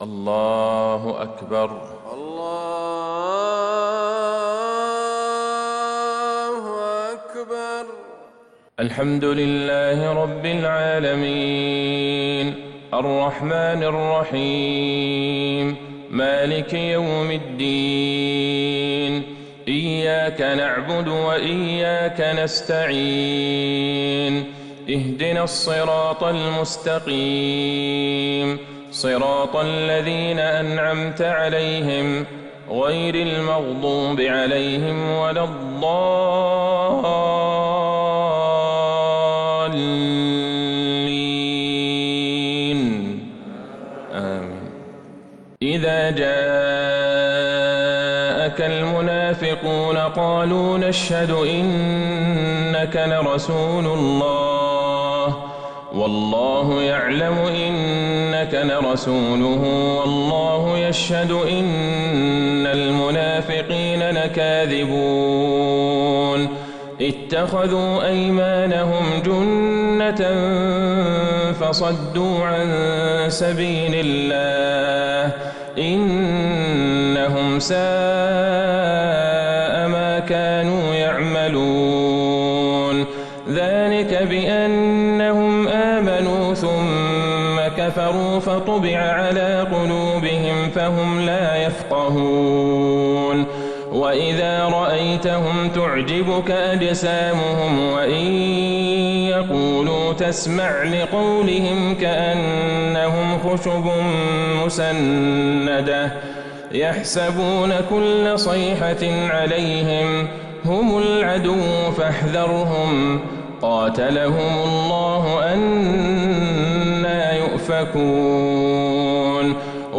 صلاة الفجر للقارئ عبدالله البعيجان 12 صفر 1442 هـ
تِلَاوَات الْحَرَمَيْن .